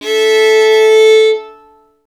STR FIDDL 0D.wav